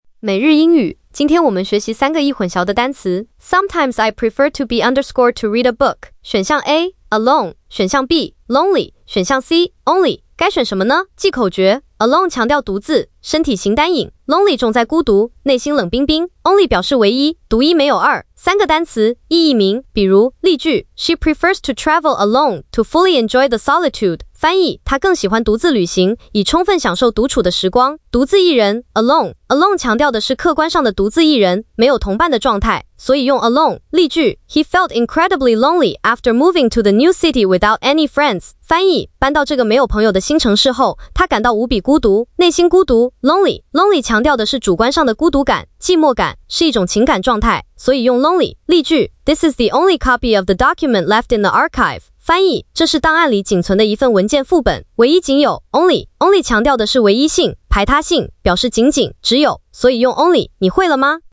🎧 语音讲解